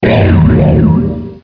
P3D-Legacy / P3D / Content / Sounds / Cries / 563.wav